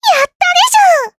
Taily-Vox_Happy4_jp.wav